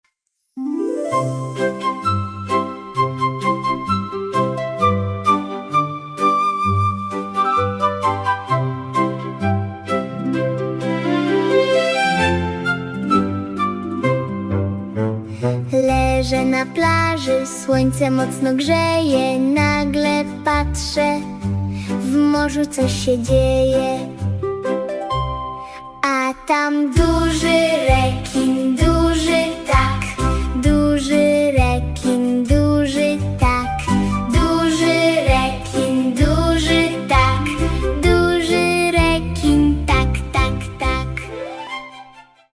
piosenka logorytmiczna
✔ nagranie wokalne